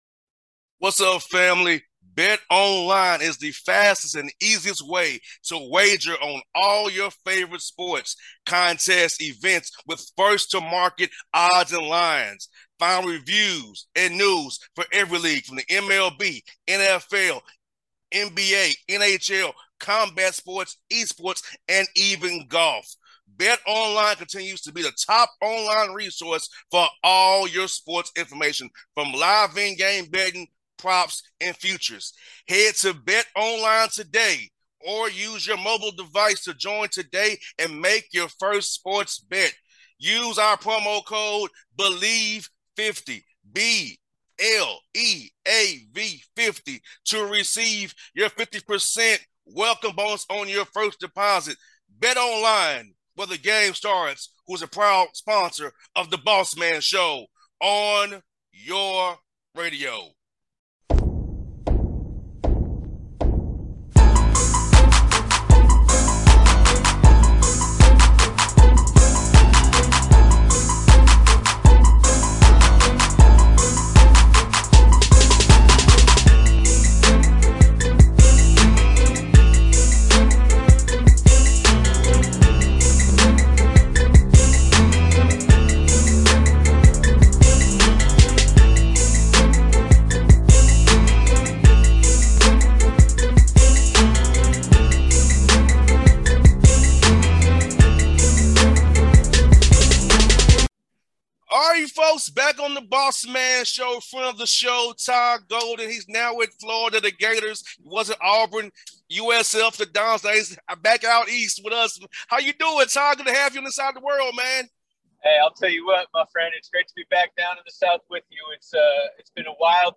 Todd Golden Interview